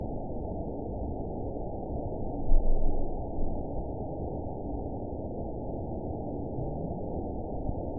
event 911047 date 02/08/22 time 23:00:56 GMT (3 years, 3 months ago) score 7.82 location TSS-AB02 detected by nrw target species NRW annotations +NRW Spectrogram: Frequency (kHz) vs. Time (s) audio not available .wav